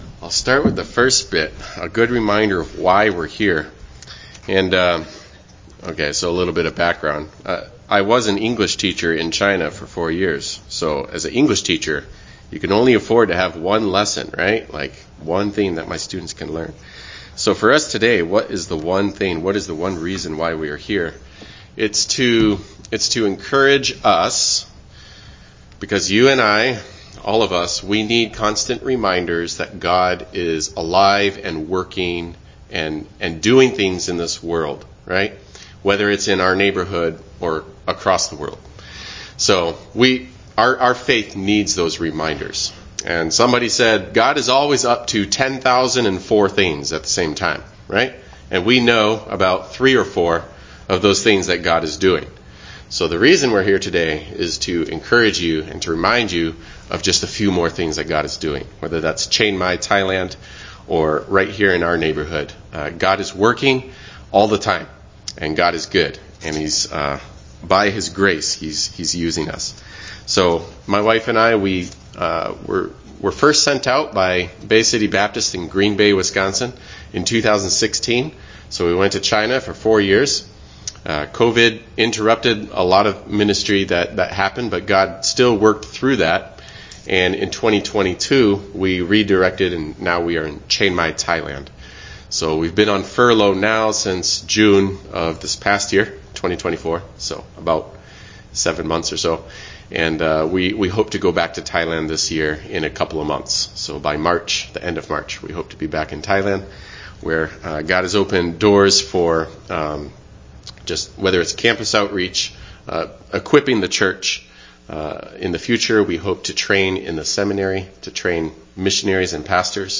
John 9 Service Type: Sunday morning worship service Bible Text